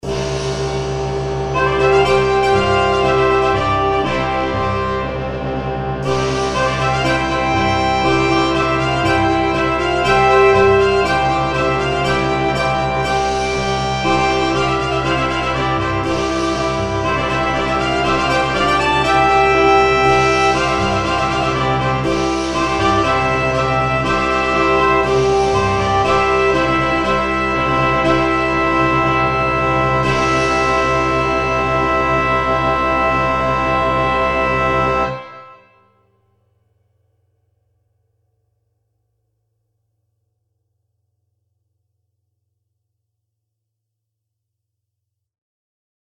3 Trumpets
2 Horns in F
2 Trombones
Euphonium
Tuba
for Brass Nonet & Pecussion